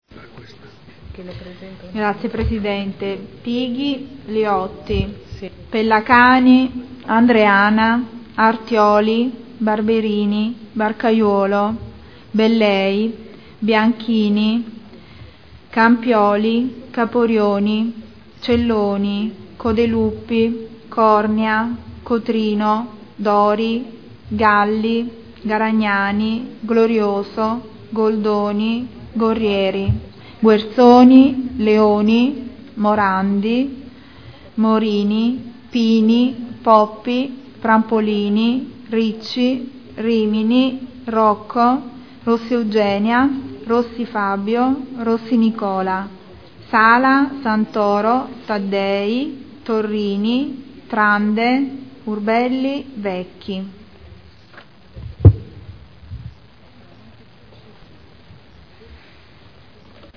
Seduta del 10/09/2012 Appello
Segretario